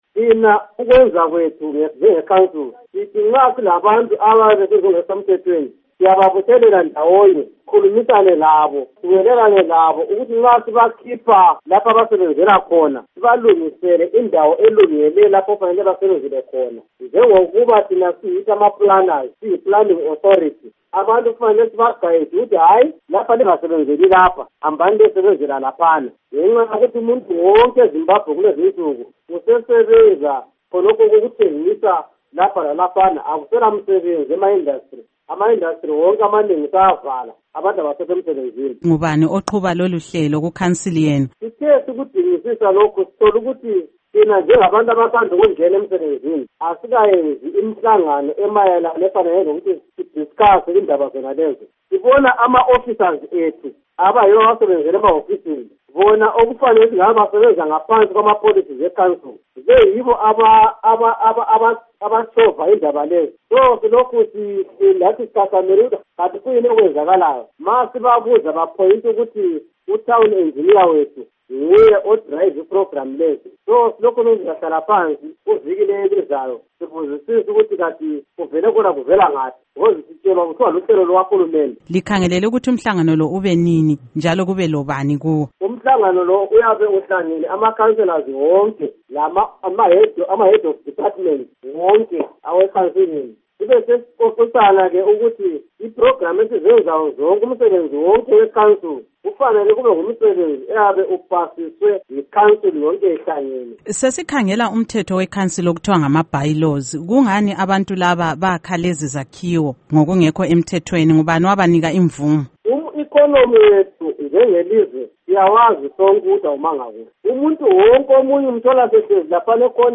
Ingxoxo LoKhansila Lazarus Ndlovu